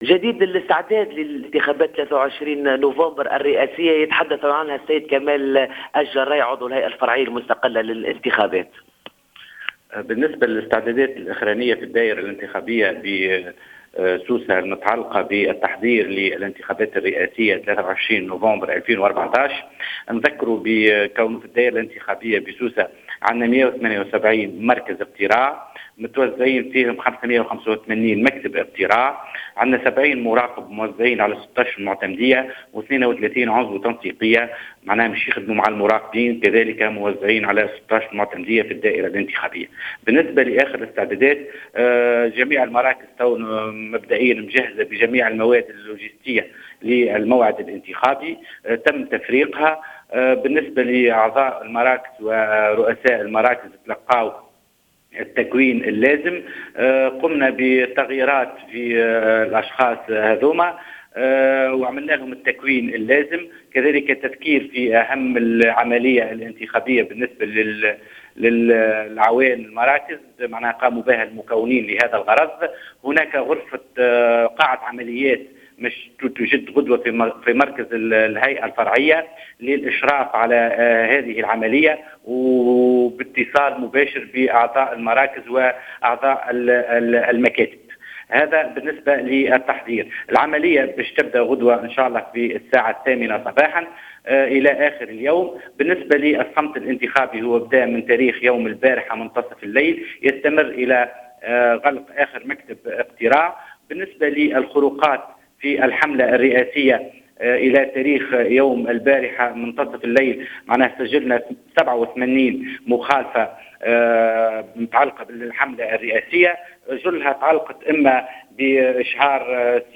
أكد كمال جراي رئيس الهيئة الفرعية للانتخابات بسوسة في تصريح لجوهرة أف أم اليوم السبت إتمام الاستعدادات للاستحقاق الانتخابي غدا الأحد 23 نوفمبر 2014 بـ178 مركز اقتراع و585 مكتب اقتراع موزعة بمختلف أنحاء الولاية.